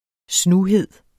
Udtale [ ˈsnuˌheðˀ ]